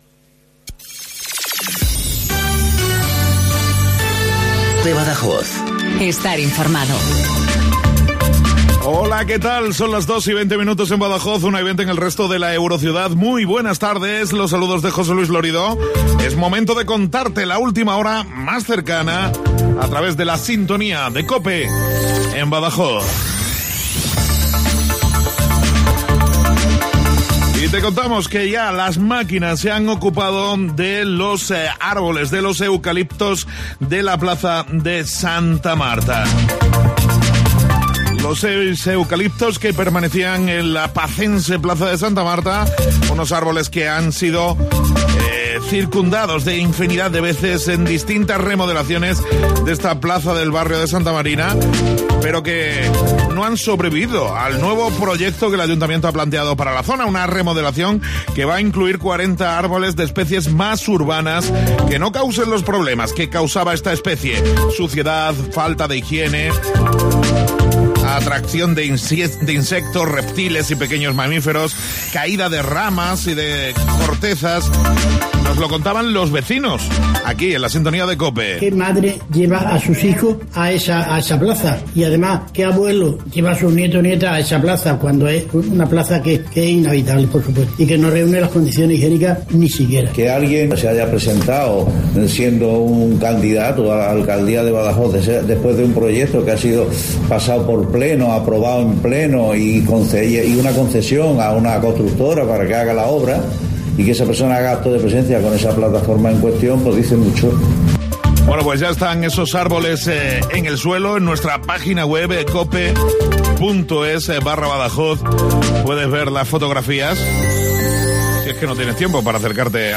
INFORMATIVO LOCAL BADAJOZ 1420